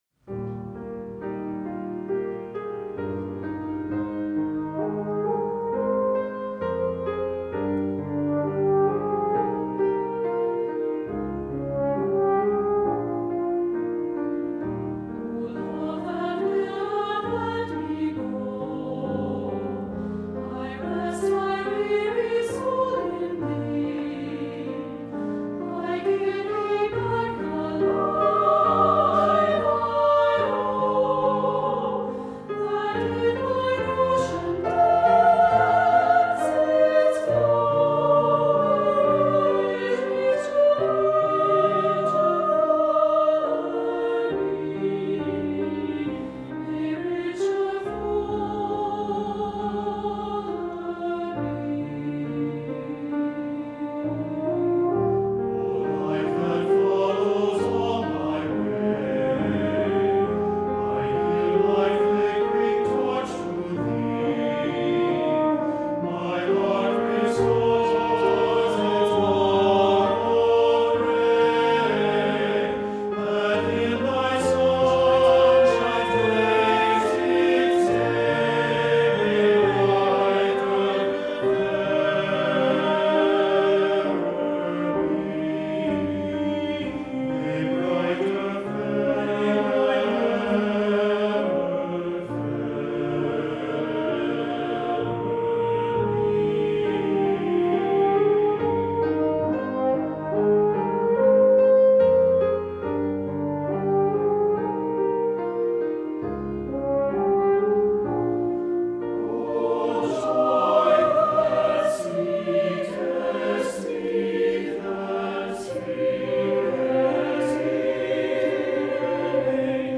Voicing: SATB, French Horn and Piano